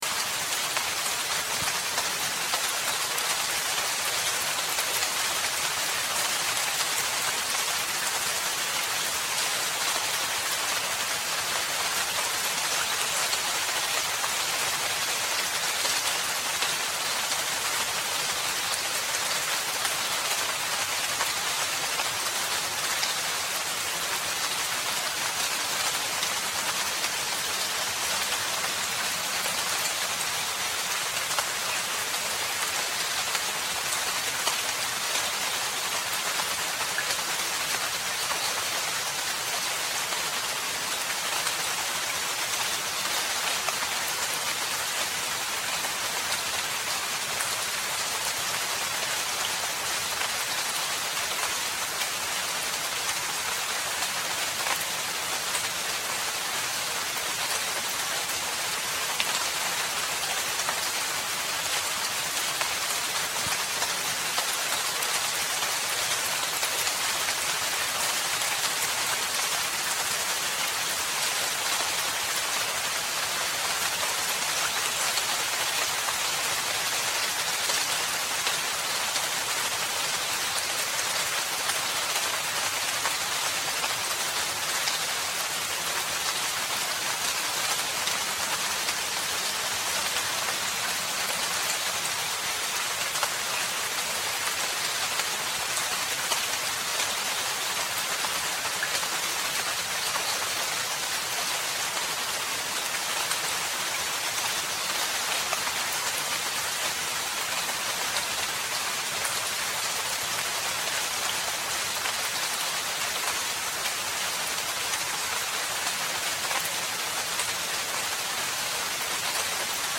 Forest Rainfall: 1 Hour of Pure Nature Sounds for Calm
Before you press play, you should know this: all advertisements for Rain Sounds, Rain To Sleep, Rainy Day, Raining Forest, Rainy Noise are placed gently at the very beginning of each episode.